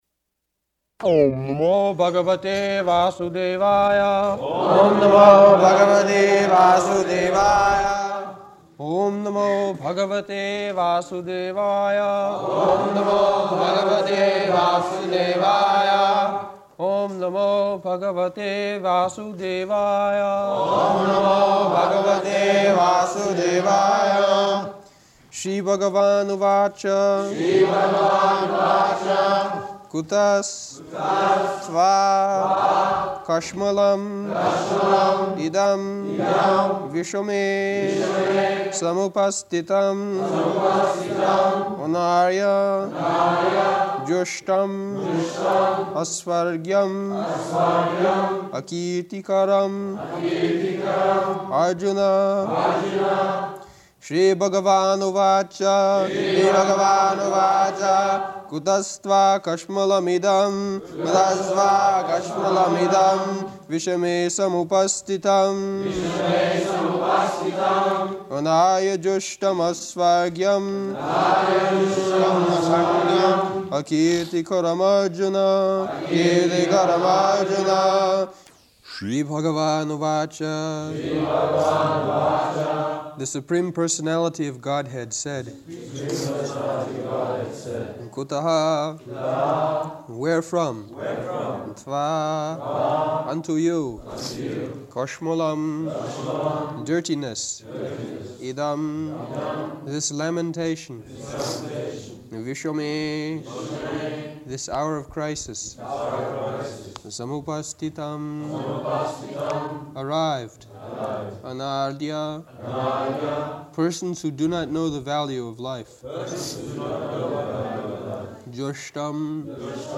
August 3rd 1973 Location: London Audio file
[leads chanting of verse] [Prabhupāda and devotees repeat]